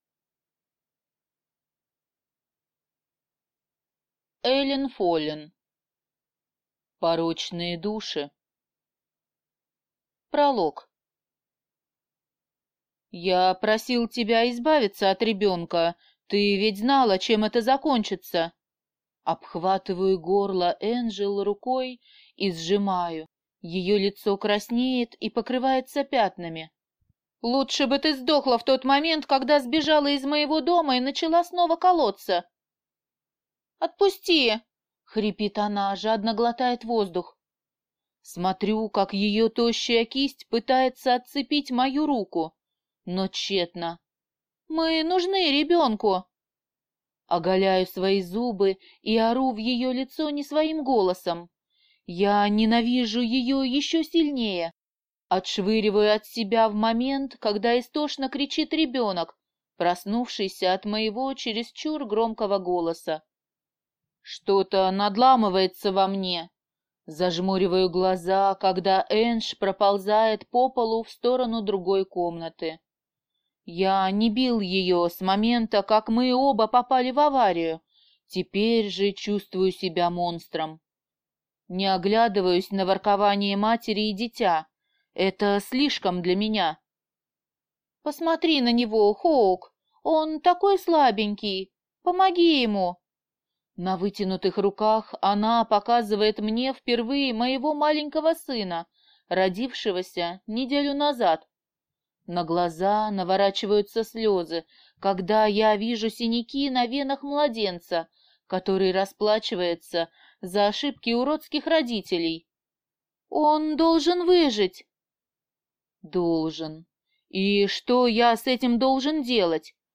Аудиокнига Порочные души | Библиотека аудиокниг